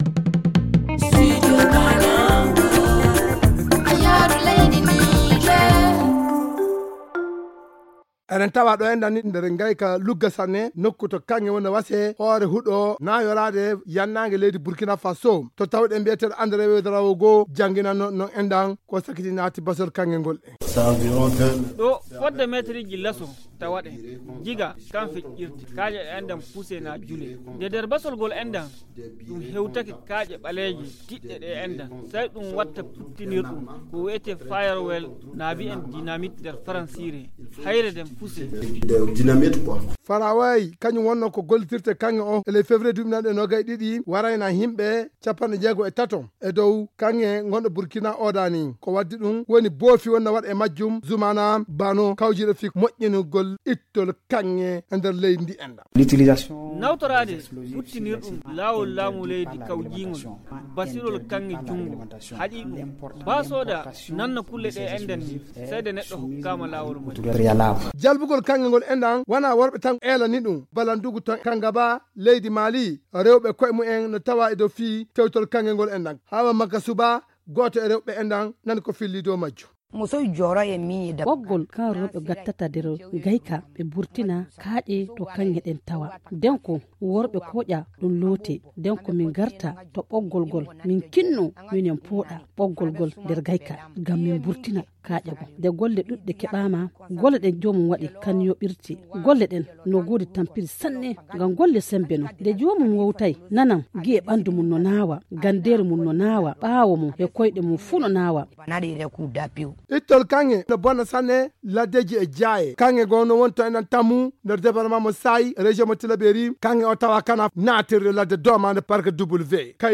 Le magazine en fulfuldé